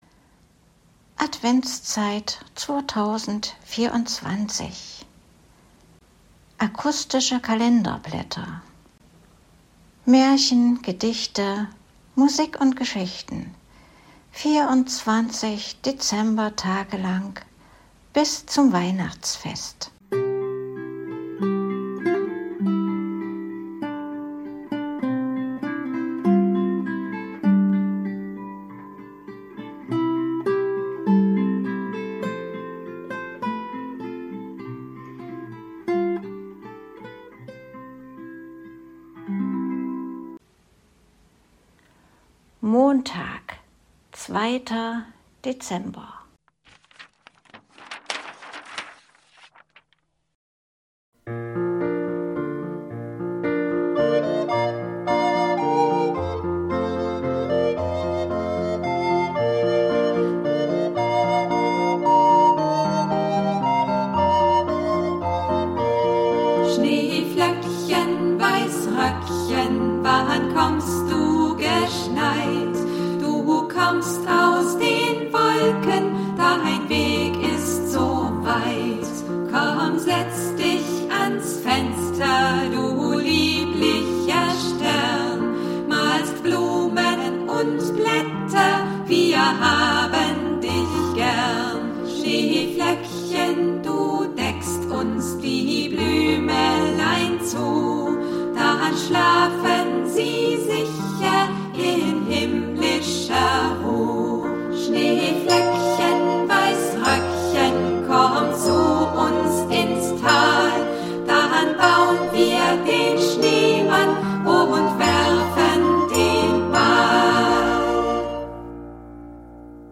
liest die Geschichten